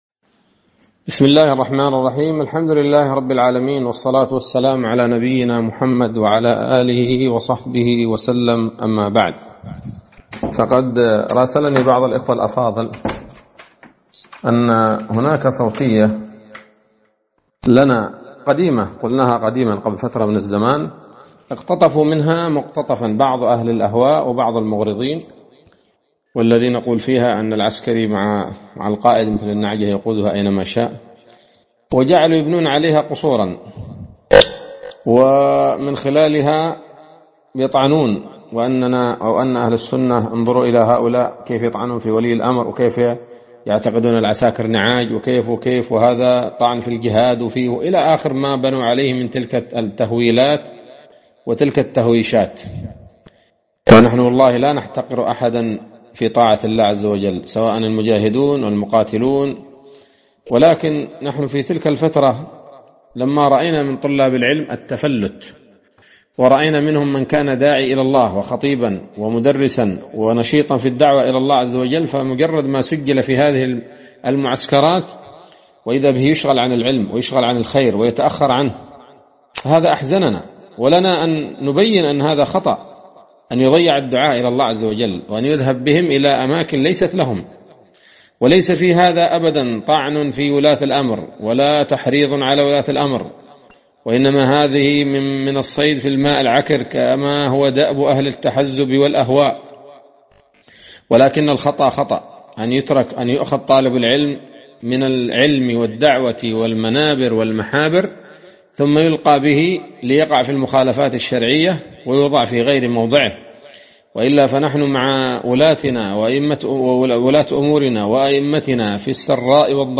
كلمة وبيان بعنوان: ((هل تحذير طلاب العلم من ترك العلم والذهاب للعسكرة طعن في ولاة الأمور؟ )) ليلة الخميس 9 ذو الحجة 1446هـ، بمدينة القاهرة - مصر